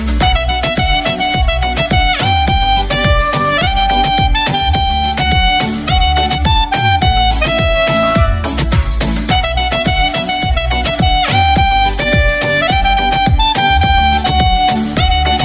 Covers - Canciones / Música Clasica